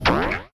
bubble1.wav